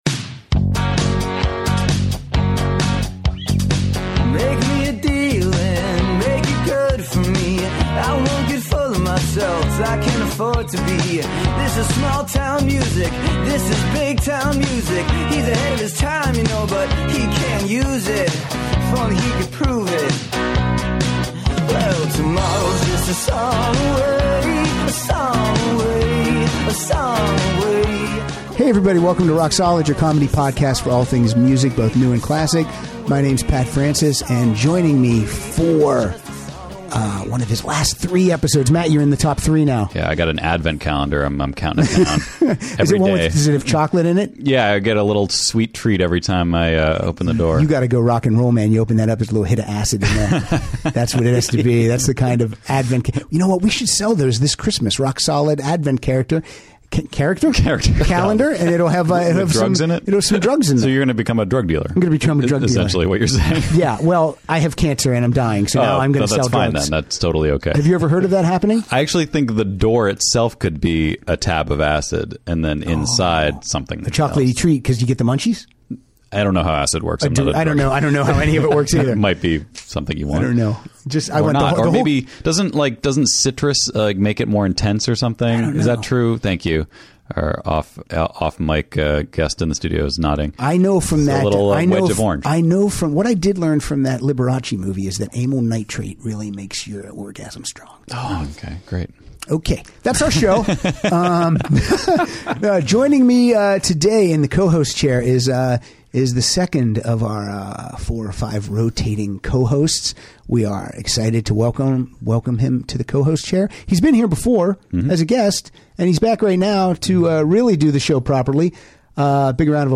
Once this episode starts it never stops because we're playing songs with "Go" in the title!